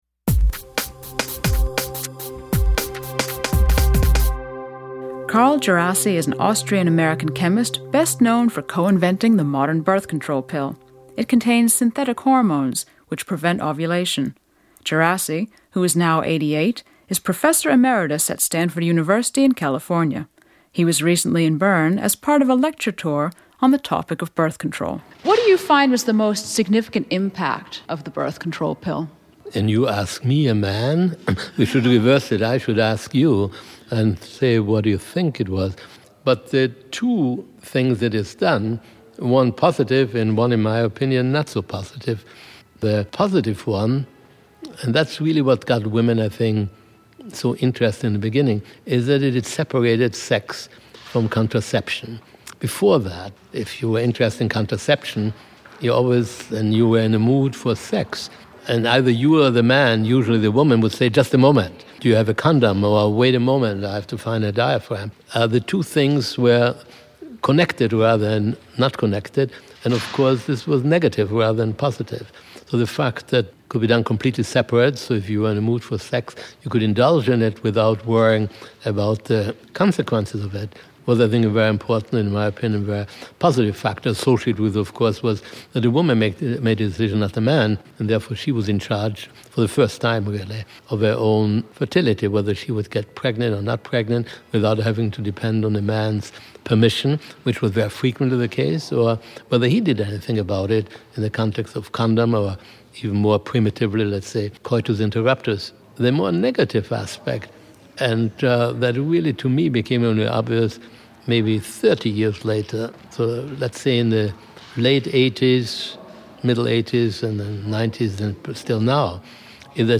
Austrian-American chemist Carl Djerassi, co-inventor of The Pill, talks about the separation of sex and contraception. He notes that the development has not been a completely positive one.